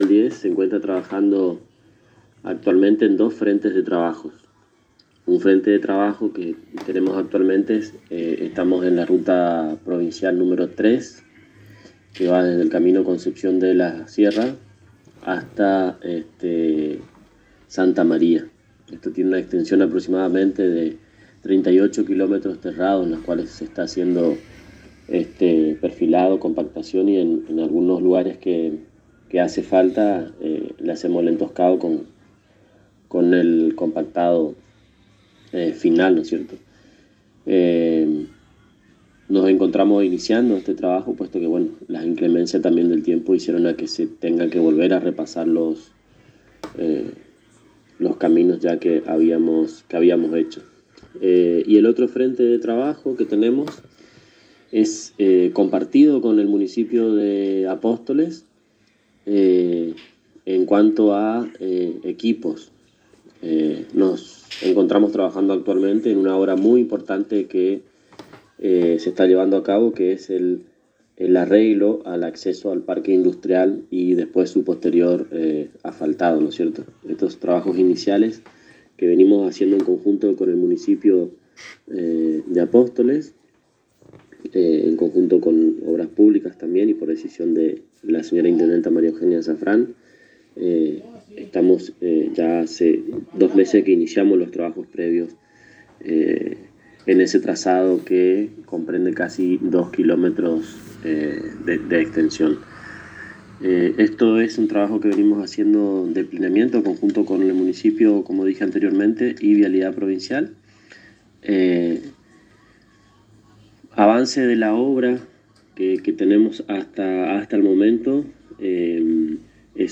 en diálogo con la ANG